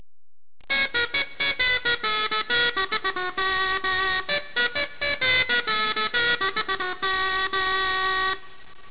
CRUMHORN
Crumhorn Sound Clips